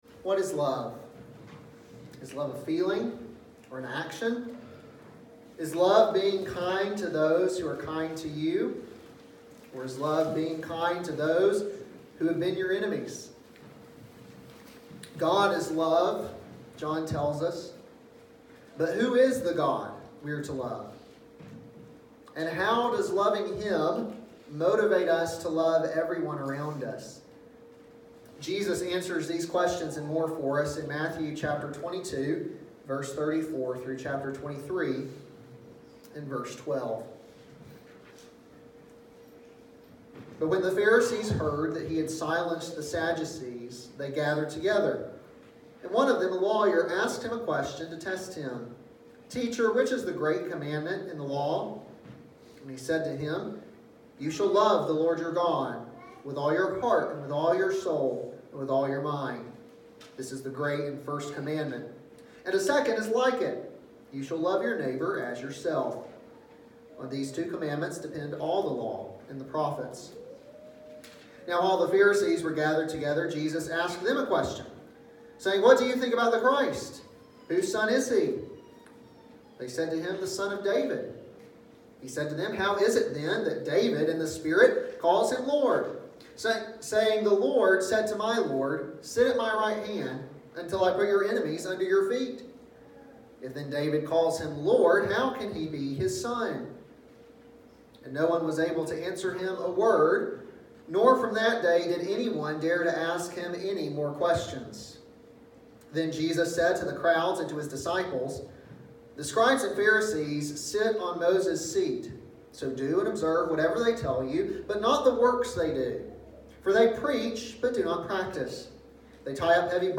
an expository sermon